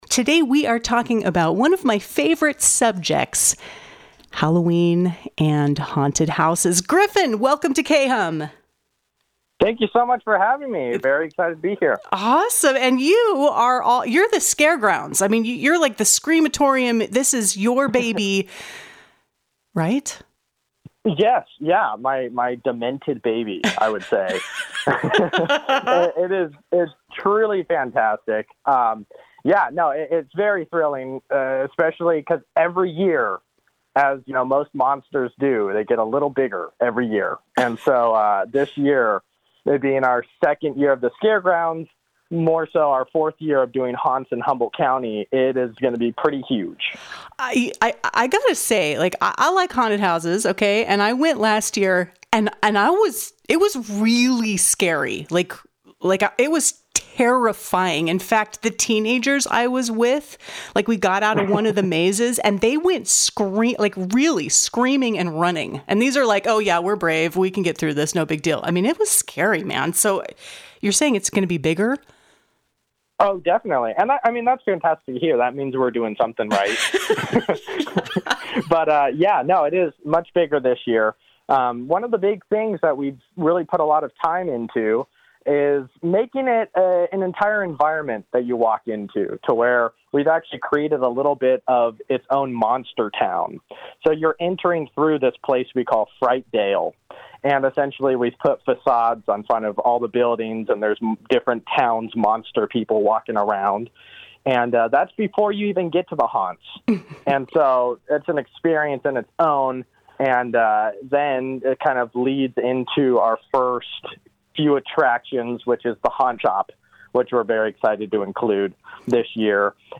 Live at KHUM